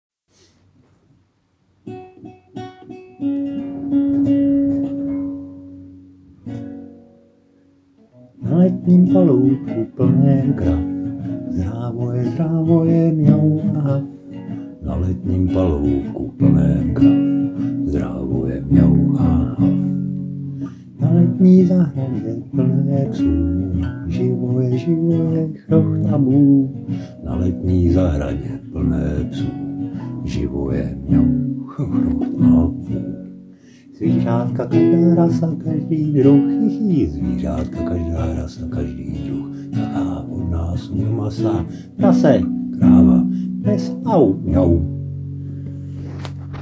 Humor
Duet